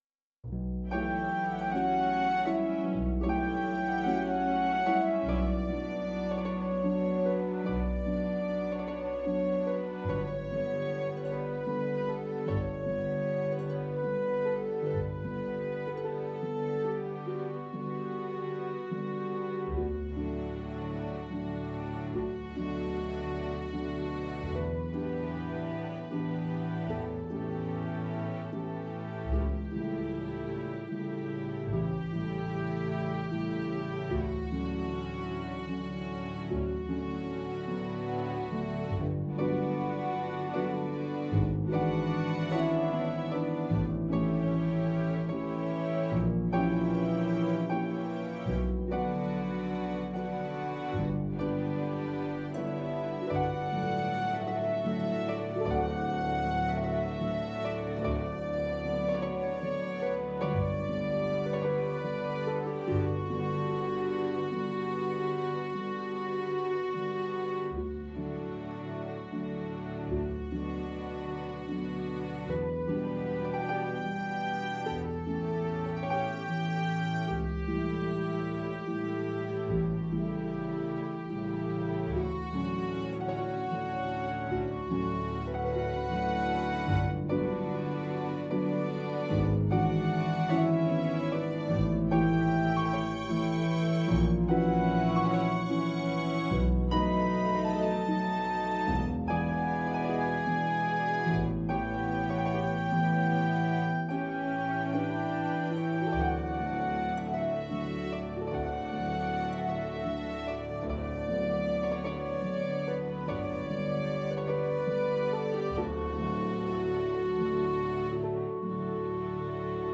Play (or use space bar on your keyboard) Pause Music Playalong - Piano Accompaniment transpose reset tempo print settings full screen
Violin
D major (Sounding Pitch) (View more D major Music for Violin )
6/8 (View more 6/8 Music)
.=58 Andante con moto (View more music marked Andante con moto)
Classical (View more Classical Violin Music)